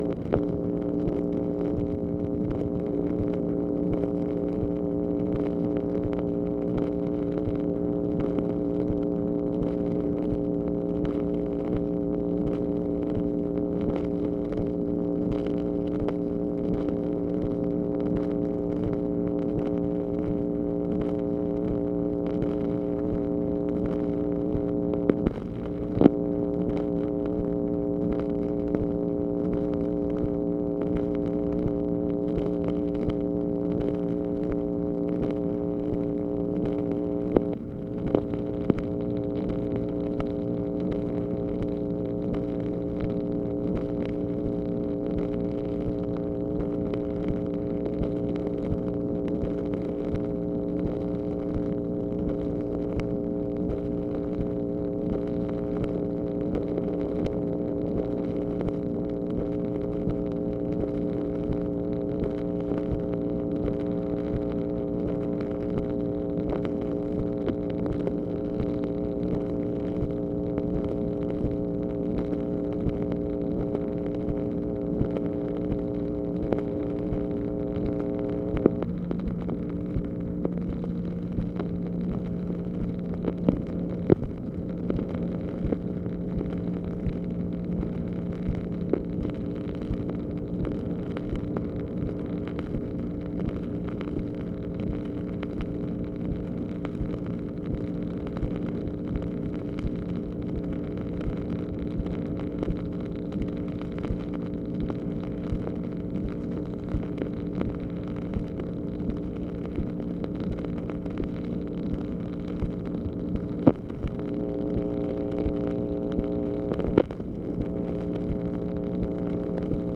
MACHINE NOISE, March 18, 1965
Secret White House Tapes | Lyndon B. Johnson Presidency